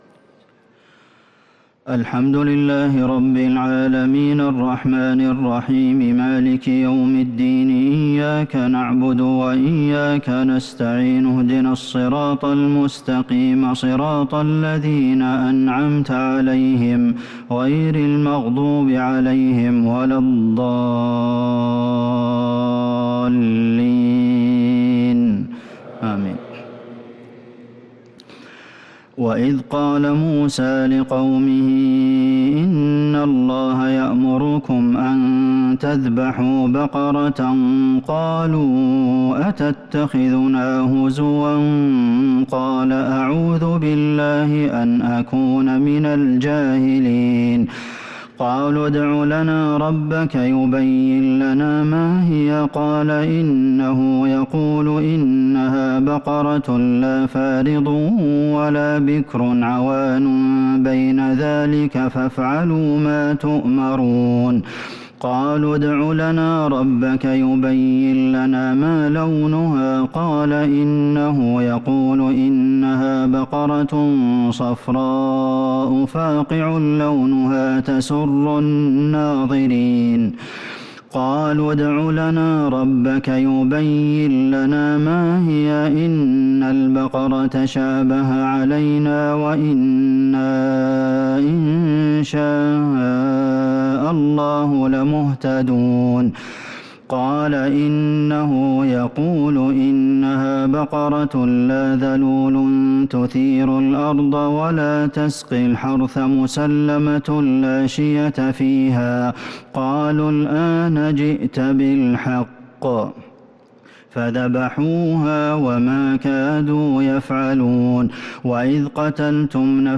صلاة التراويح ليلة 29 رمضان 1443 للقارئ عبدالمحسن القاسم - التسليمتان الاخيرتان صلاة التهجد